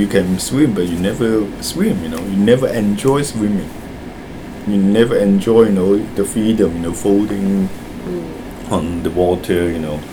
S1 = Taiwanese female S2 = Hong Kong male Context: S2 is talking about the importance of doing what you are good at, such as art.
The main problem with floating is the absence of [l] in the initial consonant cluster; and one problem with on the is that the voiced TH is pronounced as [d] .